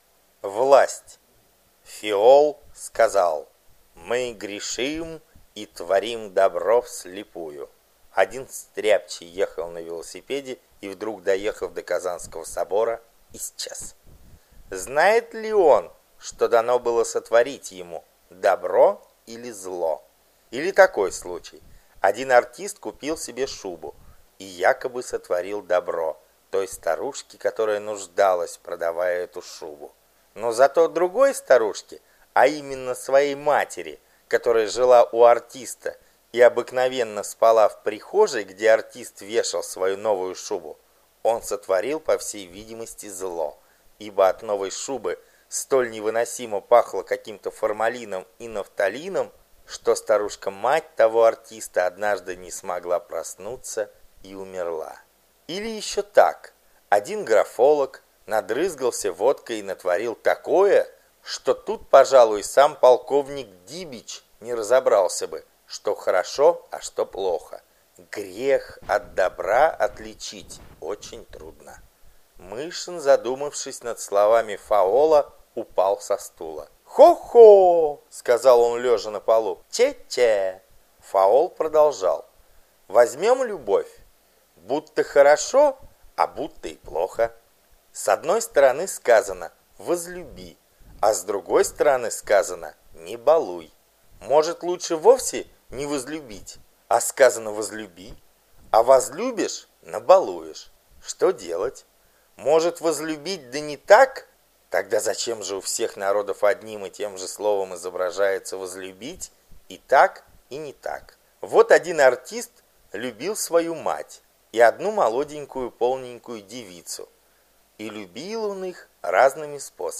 Галерея Хармс 5 часов АУДИОКНИГА